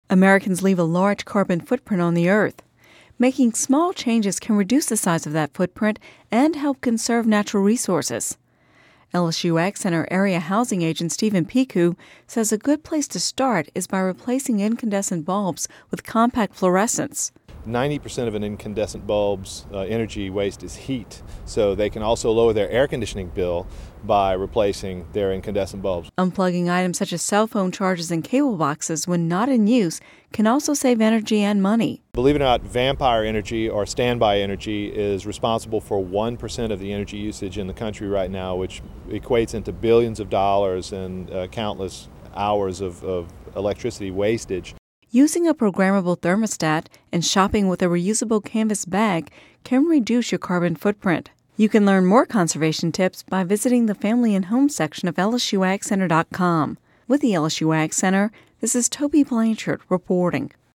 (Radio News 02/28/11) Americans leave a large carbon footprint on the Earth. Making small changes can reduce the size of that footprint and help conserve natural resources.